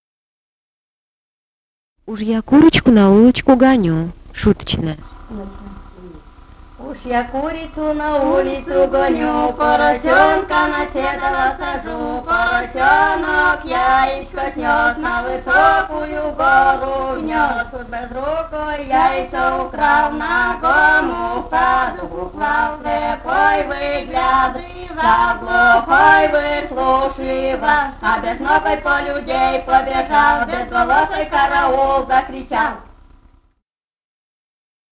Пермский край, д. Пепеляево Очёрского района, 1968 г. И1077-33